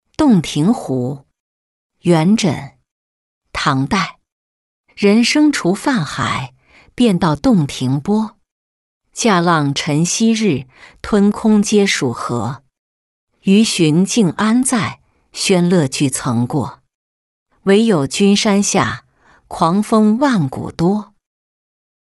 洞庭湖-音频朗读